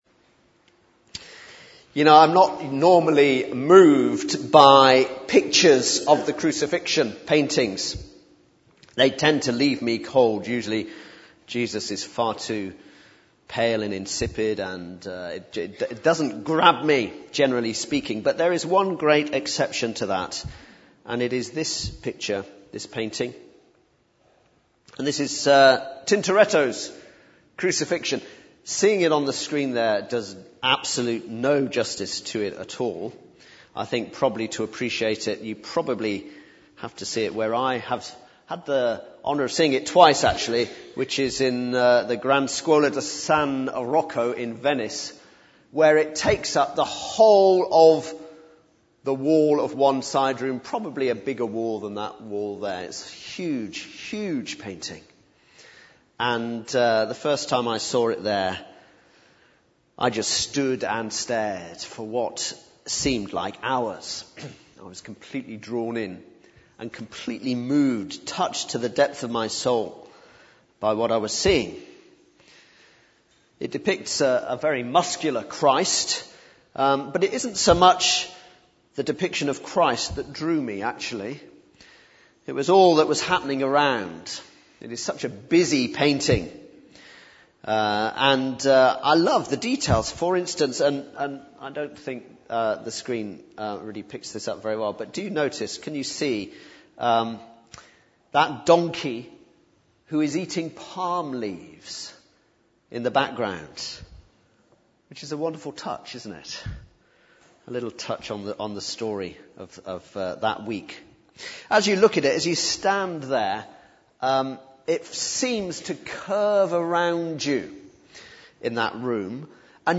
Good Friday 2015